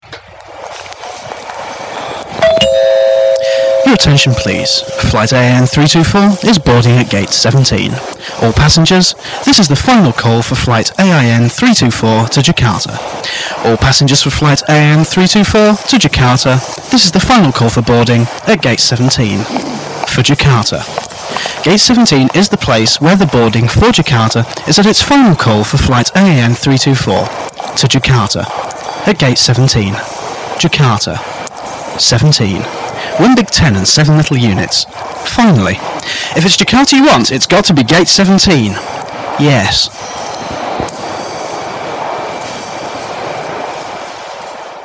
Voice Over (with airport noises)